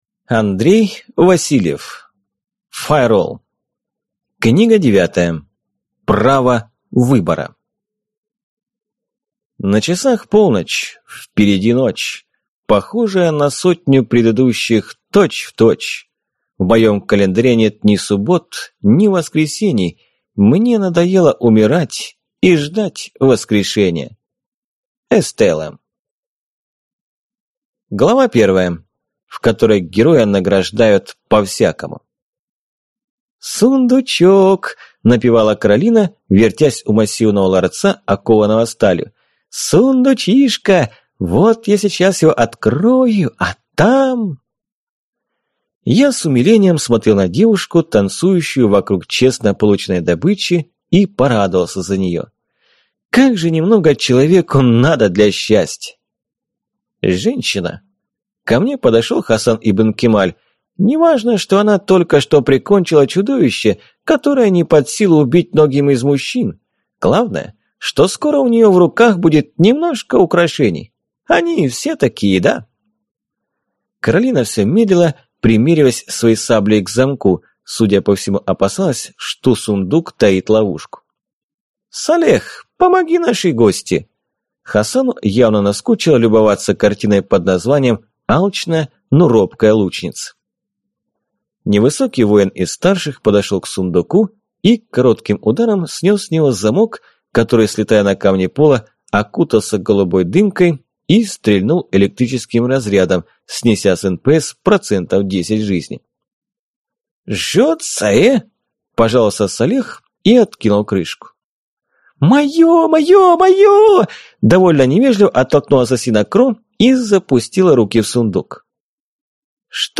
Аудиокнига Файролл. Право выбора (+Бонус) | Библиотека аудиокниг